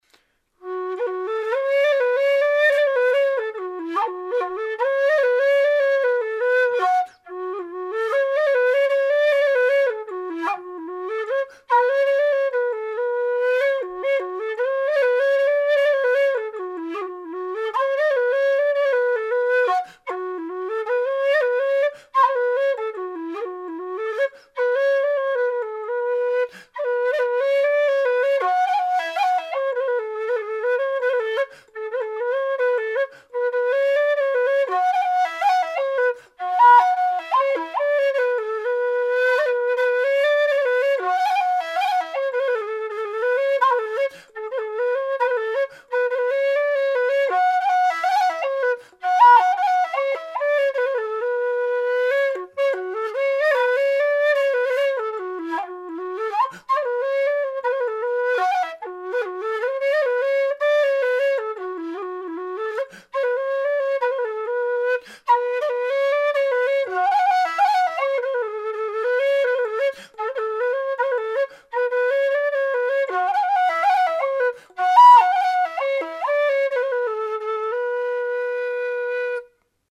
Attach:lowE_whistle.jpg Δ | low E whistle
made out of thin-walled aluminium tubing with 20mm bore